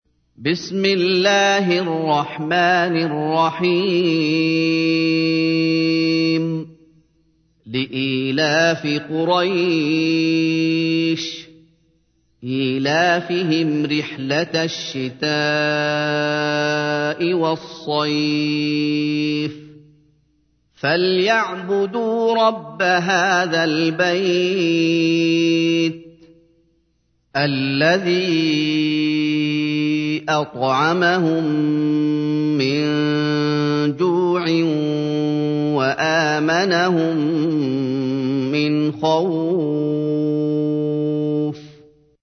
تحميل : 106. سورة قريش / القارئ محمد أيوب / القرآن الكريم / موقع يا حسين